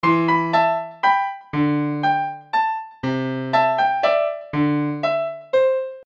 钢琴毛茸茸的
Tag: 100 bpm Hip Hop Loops Piano Loops 1.01 MB wav Key : Unknown